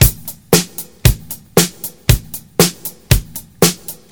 • 116 Bpm Drum Groove A# Key.wav
Free drum loop - kick tuned to the A# note. Loudest frequency: 4407Hz
116-bpm-drum-groove-a-sharp-key-pPn.wav